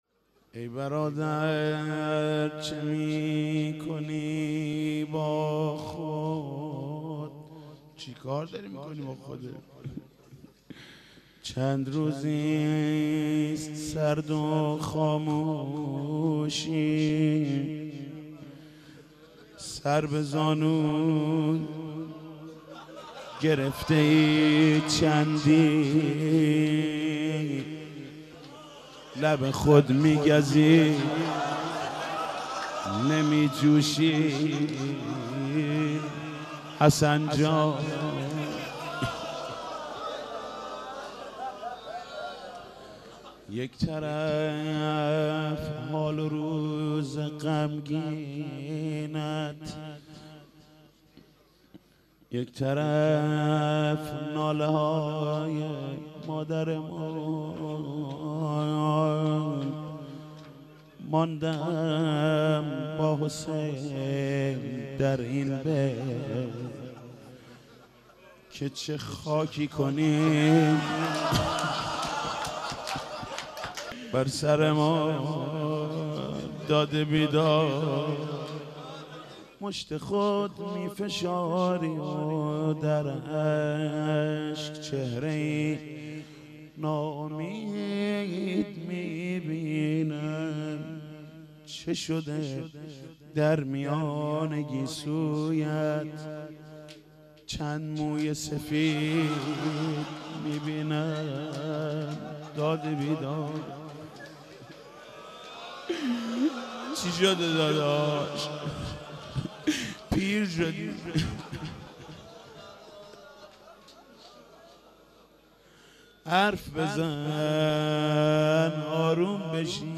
روضه حضرت زهرا(س)